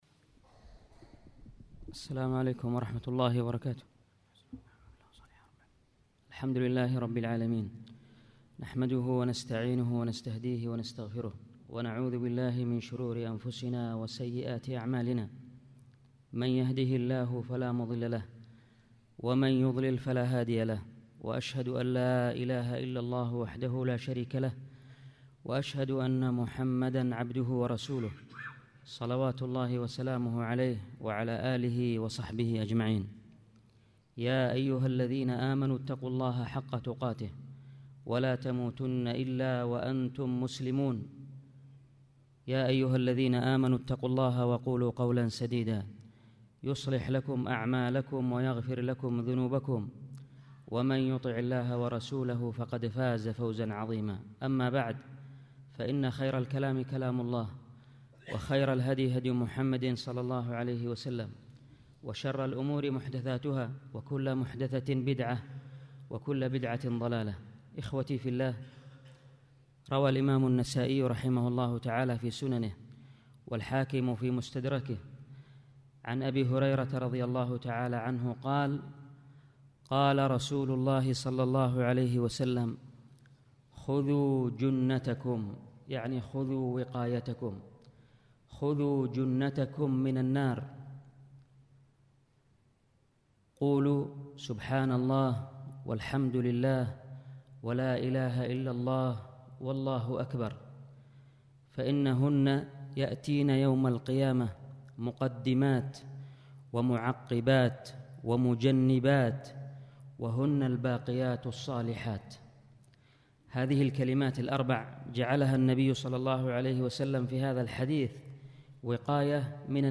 الخطبه